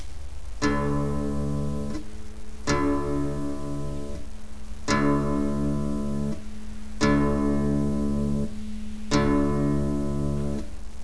El sonido de cada cuerda afinada deberia de sonar asi:
Cuarta(Re)
afinacion_re.wav